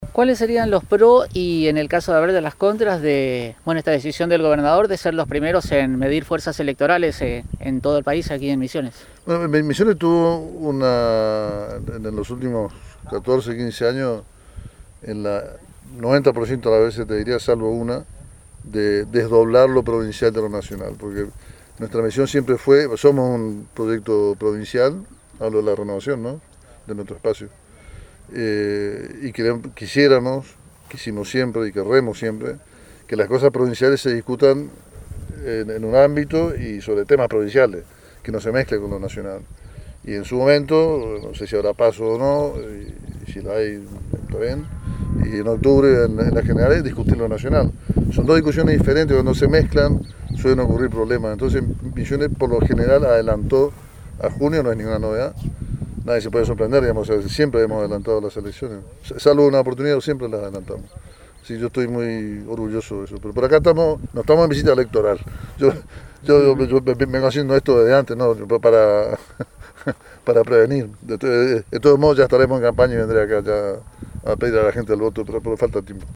En su última visita a la Capital Nacional de la Yerba Mate el Vicepresidente de la Cámara de Diputados de la Provincia de Misiones y Ex-Gobernador Hugo Passalacqua se refirió a la decisión gubernamental de ser la primer provincia del país que mida fuerzas electorales.